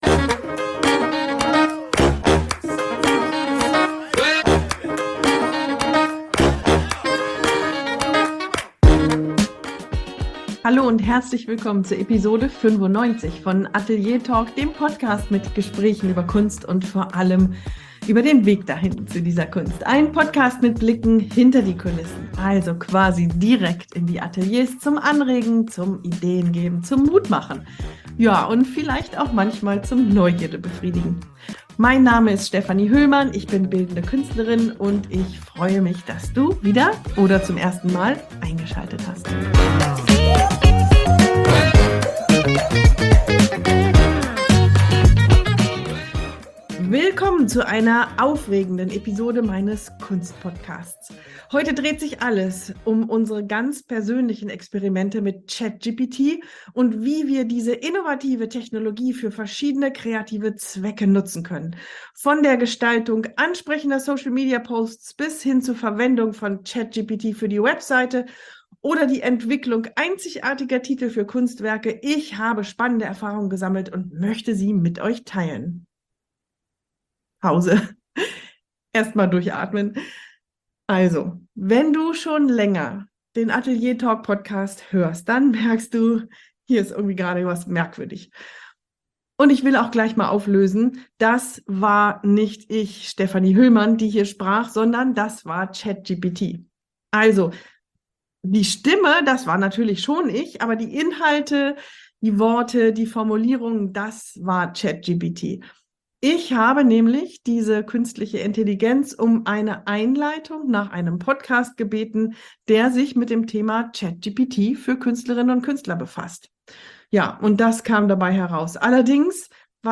Eine neue Episode, ein neues Interview, aber dieses Mal mit einem sehr besonderen Gast: In Folge #95 meines Kunst-Podcasts lasse ich dich in Echtzeit meinen Dialog mit ChatGPT über Themen rund um die Kunst miterleben. Vom Allgemeinen ins Konkrete habe ich die KI darum gebeten, mich in künstlerischen Fragen vielfältiger Art zu beraten.